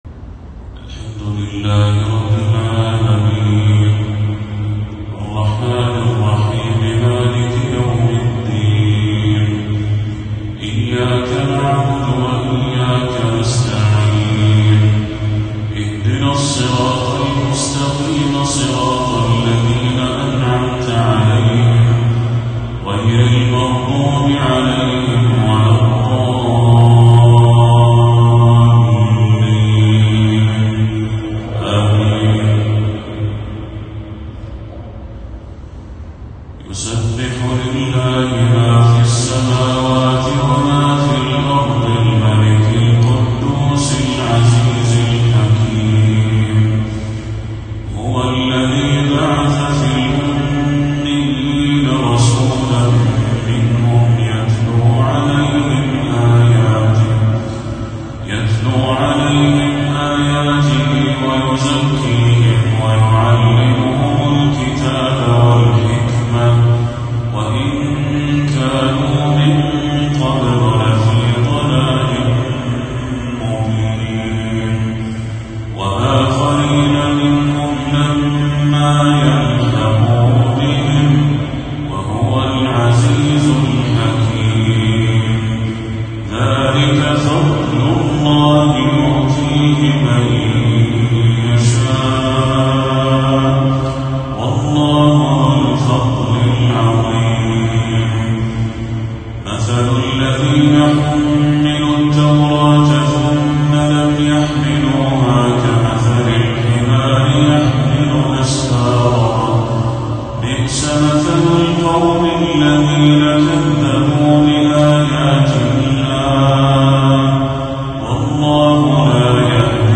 تلاوة لسورة الجمعة للشيخ بدر التركي | عشاء 13 صفر 1446هـ > 1446هـ > تلاوات الشيخ بدر التركي > المزيد - تلاوات الحرمين